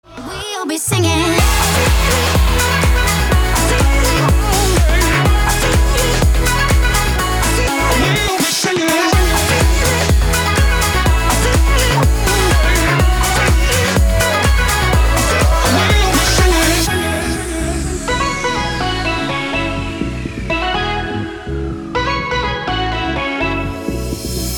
• Качество: 320, Stereo
поп
громкие
женский вокал
dance